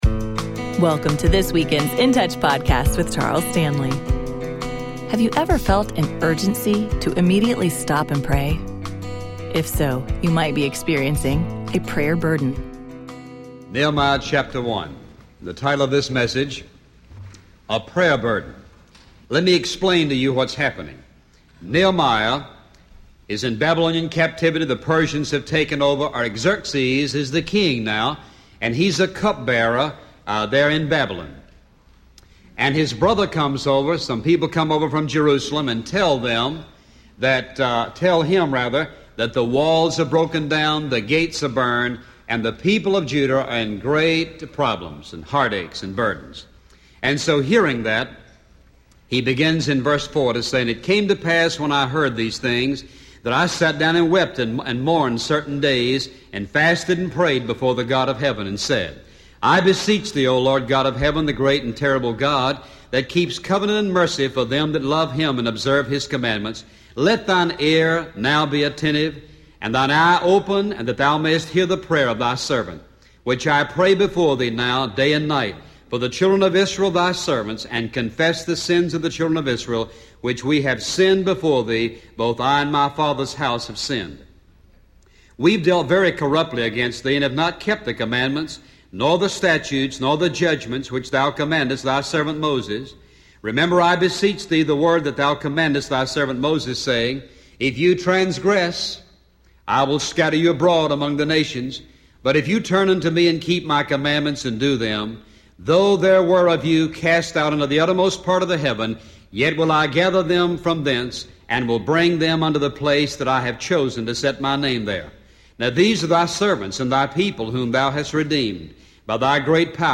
Dr. Charles Stanley and In Touch Ministries’ daily radio program.